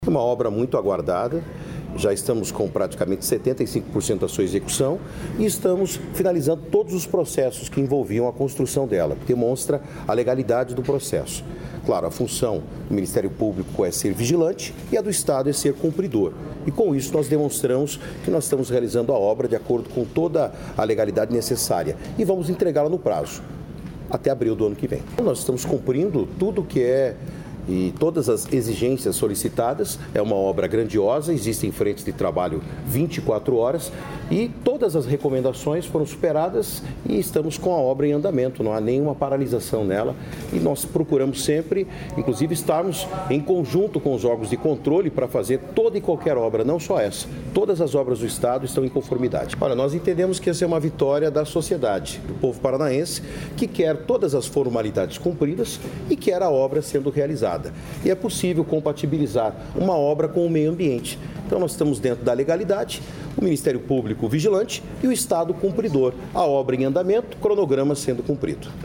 Sonora do secretário da Infraestrutura e Logística, Sandro Alex, sobre legalidade ambiental da Ponte de Guaratuba